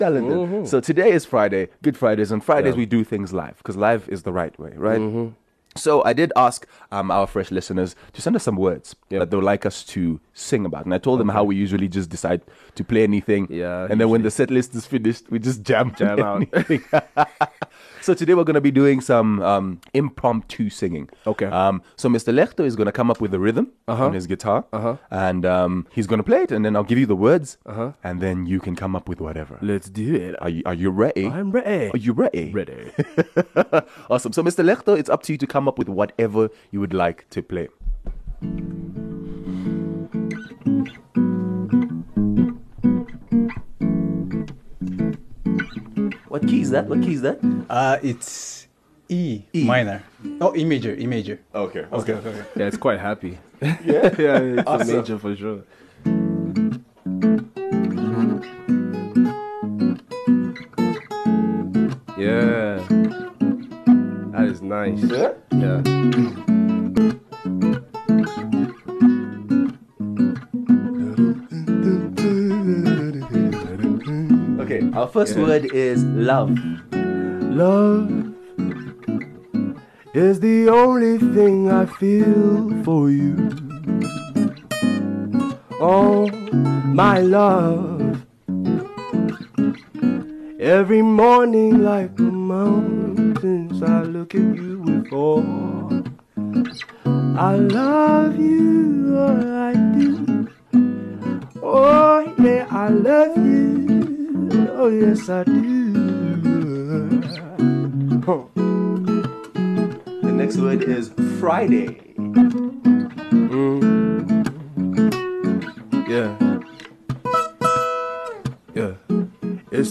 in studio for a live jam